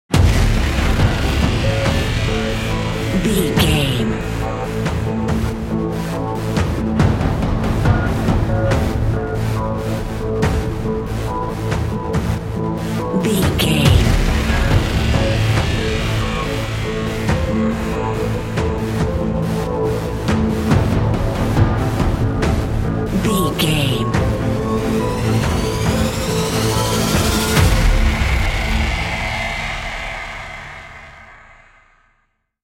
Aeolian/Minor
Fast
synthesiser
percussion